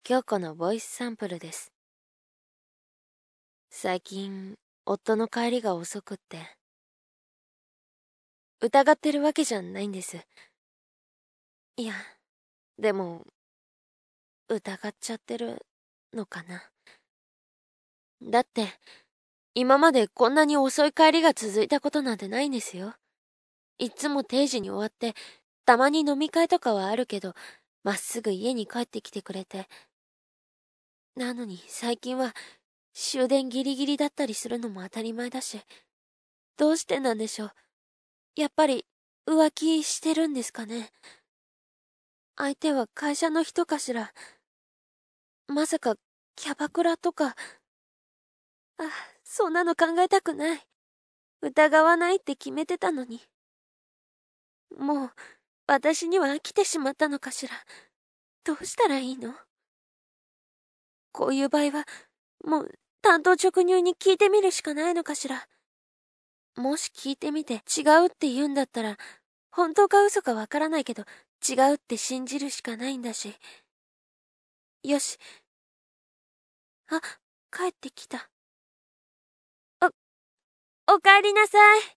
コメント 　サンプルはファイルサイズを軽くするために少し音質が悪くなってますので、実際はもう少しクリアだと思います。
普通めなお姉さんというか奥様。